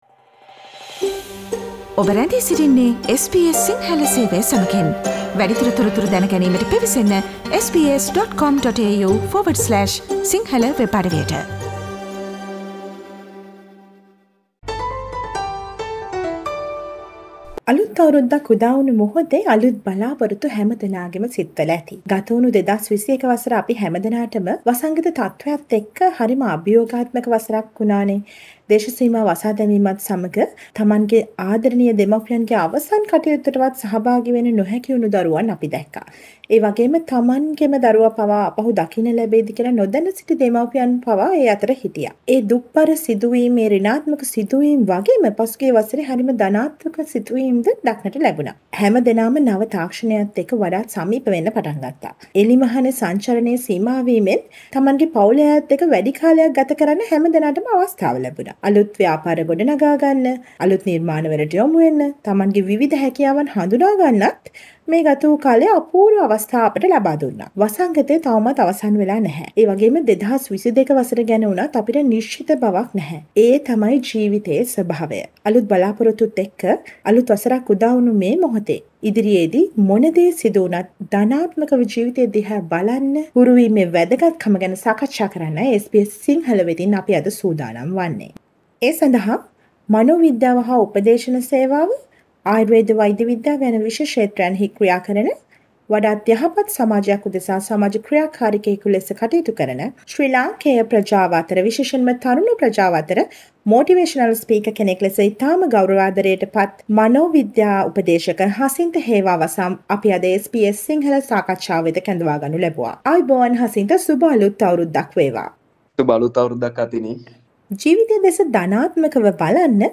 SBS සිංහල සිදු කළ සාකච්ඡාවට